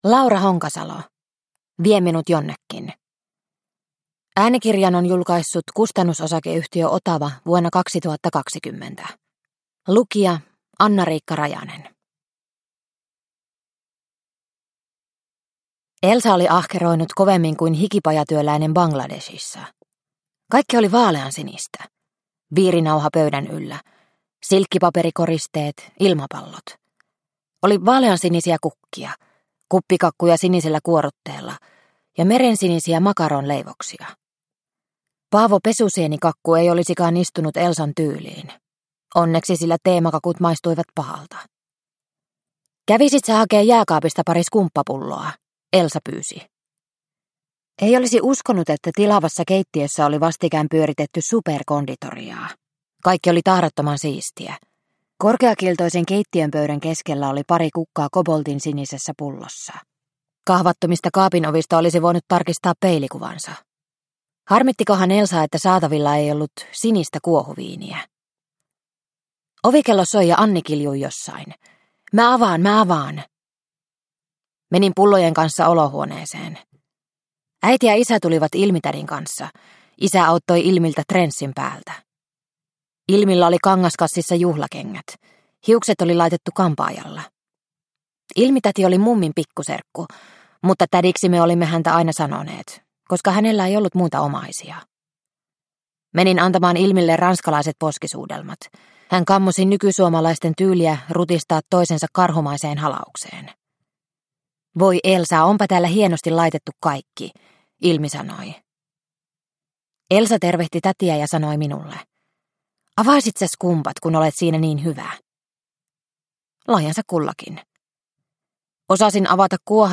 Vie minut jonnekin – Ljudbok – Laddas ner